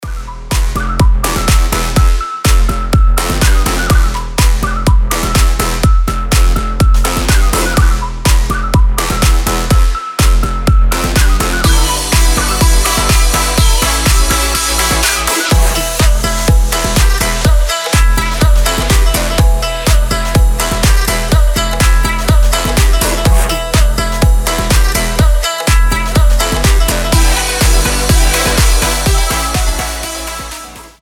Позитивная еврейская песня